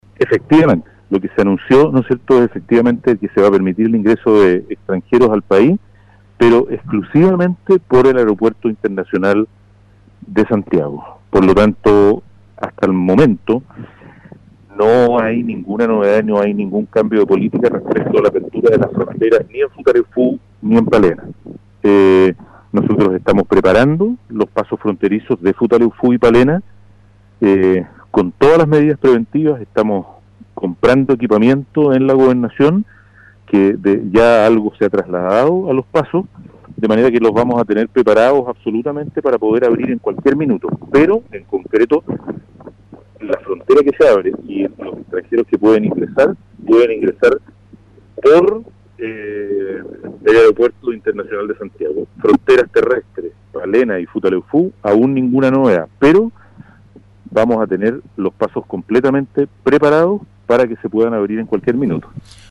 La autoridad, en entrevista con radio Estrella del Mar de Palena, manifestó que hasta ahora no habrá ingreso de personas por estos pasos fronterizos, ya que la autorización es válida solo para el Aeropuerto Arturo Merino Benítez de Santiago.